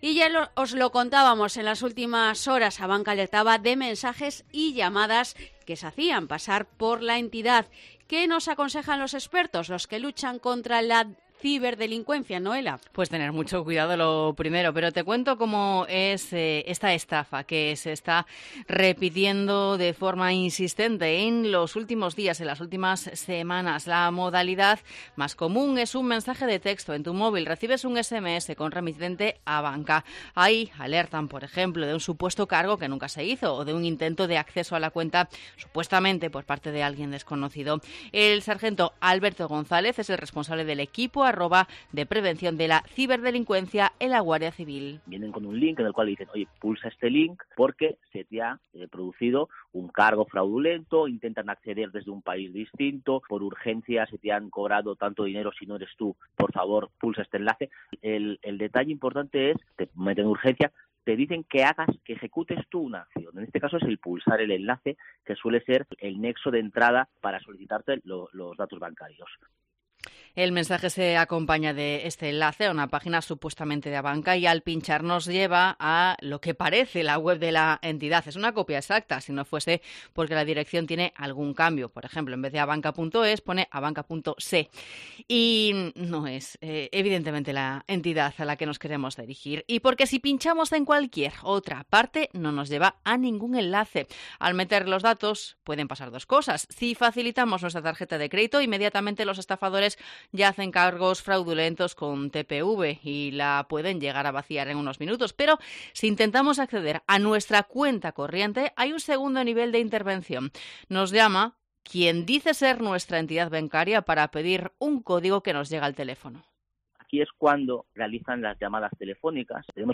Intento de estafa que se hace pasar por ABANCA: hablamos con la Guardia Civil